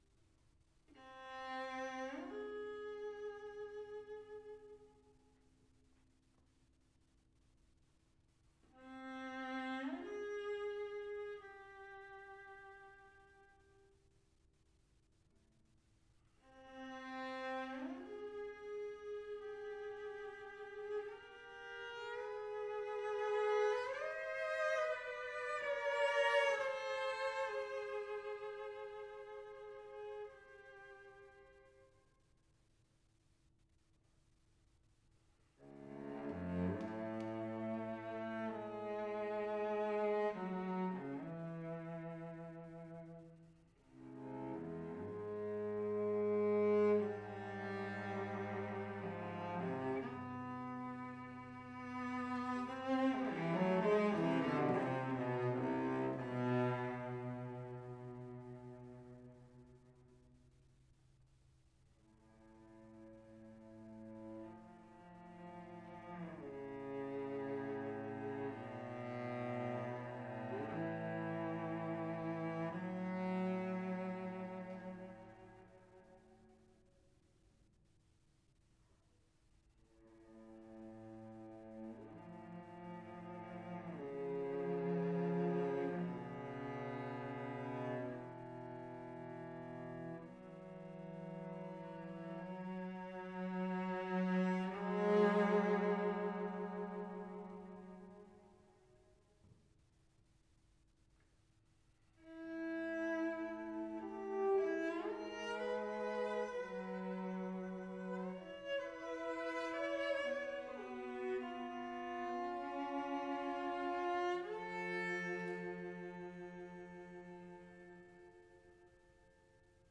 Yen and Yen, for cello duo